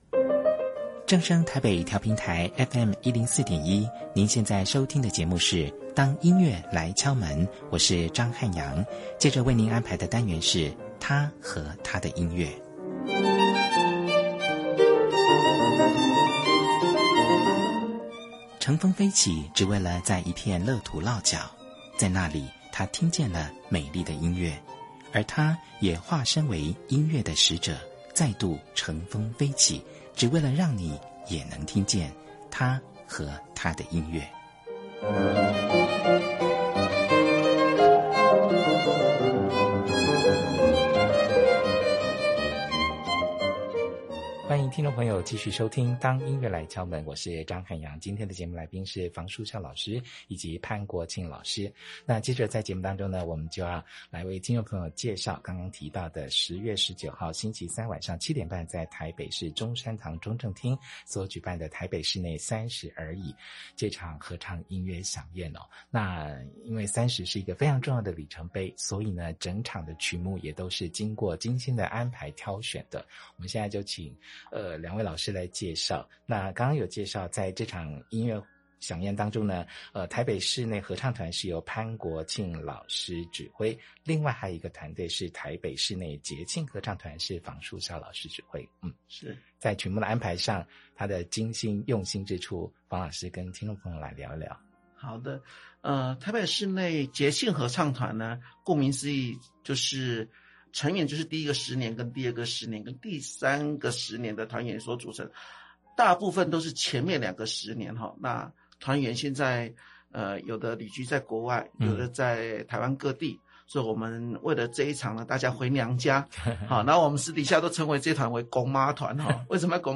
兩位不同世代的台北室內合唱團成員，將在本集節目對話中，交織出什麼精彩內容，歡迎收聽。